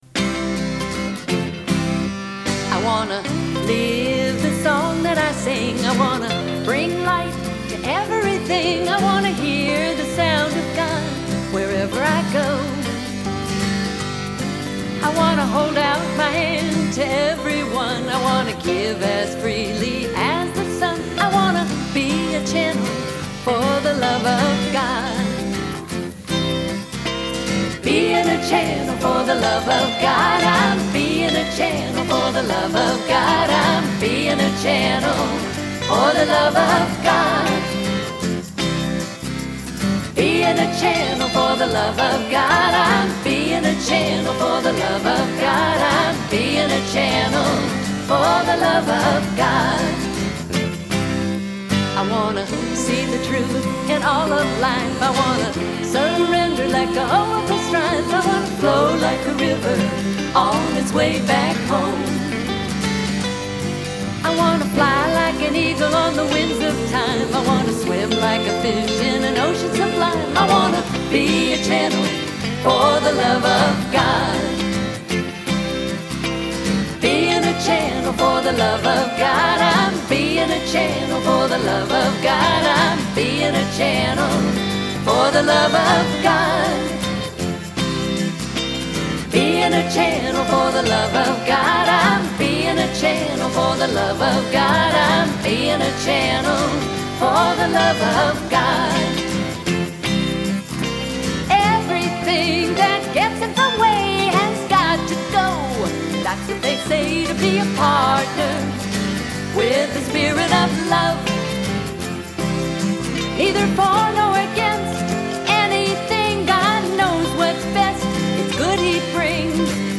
1. Devotional Songs
Major (Shankarabharanam / Bilawal)
8 Beat / Keherwa / Adi
Medium Fast
2 Pancham / D
6 Pancham / A
Lowest Note: p / G (lower octave)
Highest Note: S / C (higher octave)